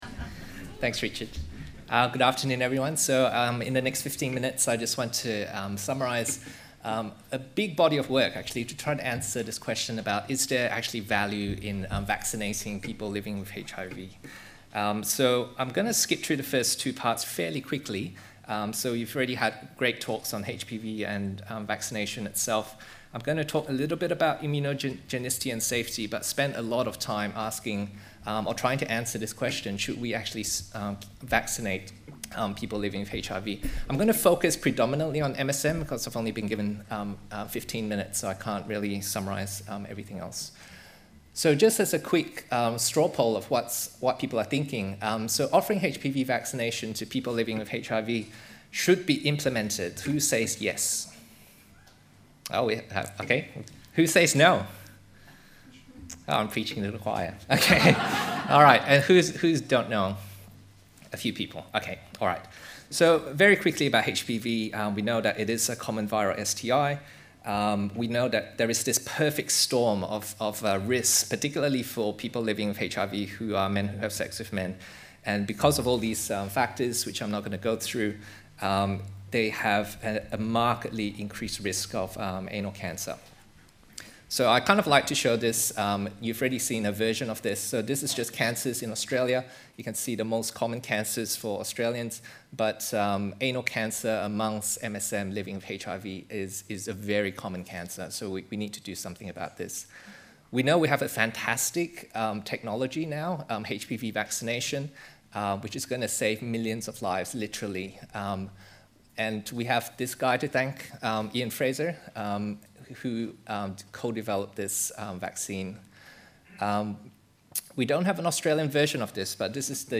Speaker Presentation